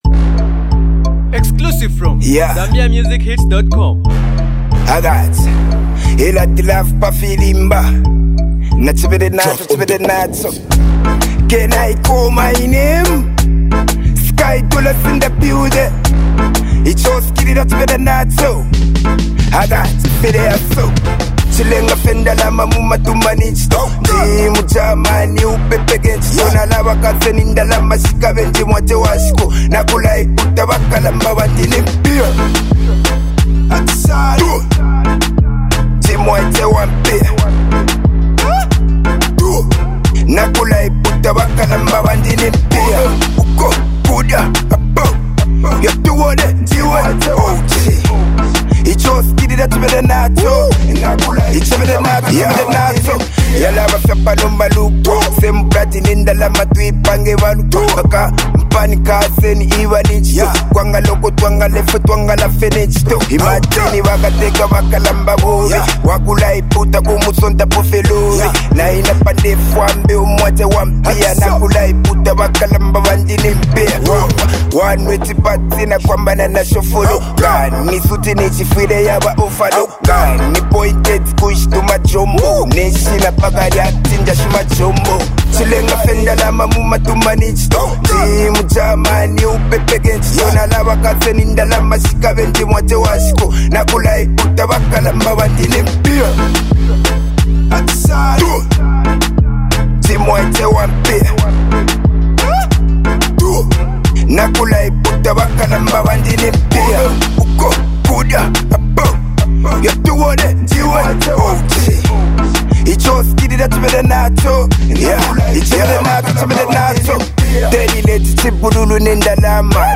Bemba hip hop